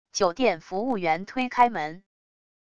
酒店服务员推开门wav音频